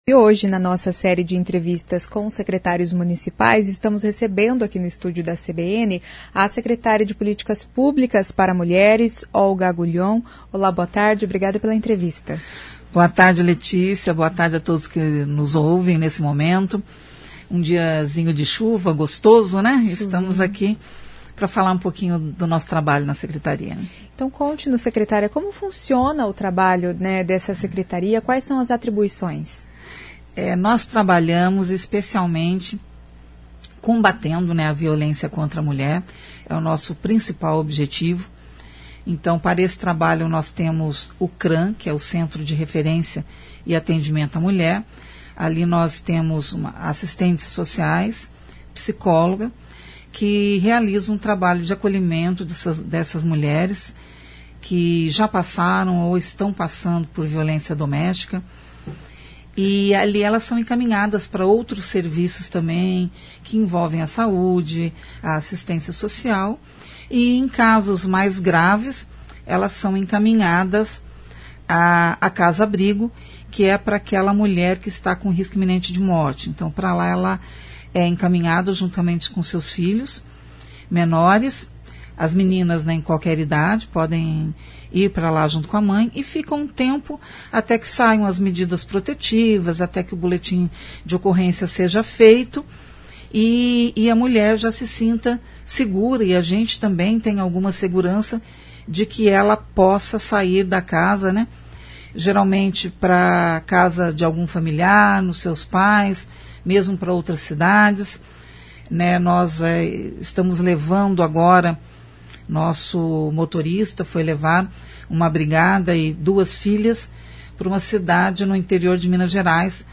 Nesta entrevista, a secretária Olga Agulhon, fala sobre a administração da secretaria.